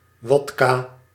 Ääntäminen
Ääntäminen France: IPA: [vɔd.ka] Haettu sana löytyi näillä lähdekielillä: ranska Käännös Ääninäyte Substantiivit 1. wodka {m} Suku: f .